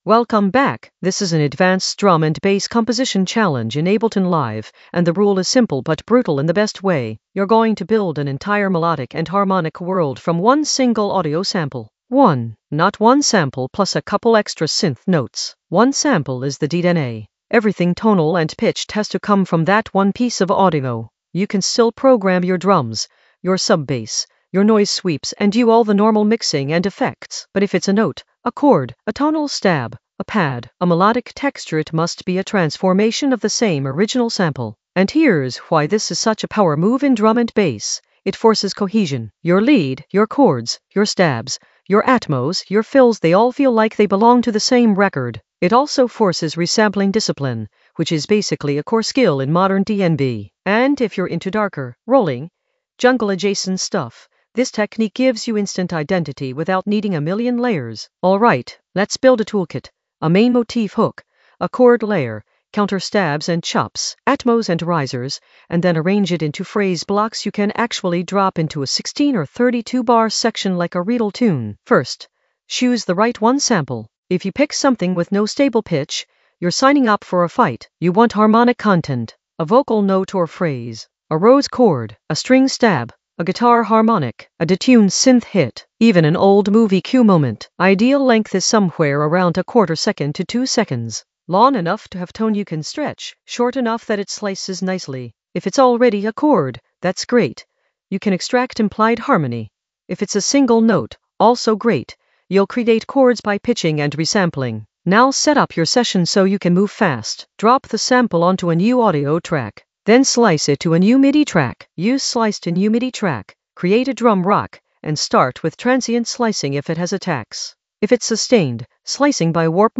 Narrated lesson audio
The voice track includes the tutorial plus extra teacher commentary.
An AI-generated advanced Ableton lesson focused on One sample melodic composition challenges in the Composition area of drum and bass production.